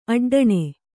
♪ aḍḍaṇe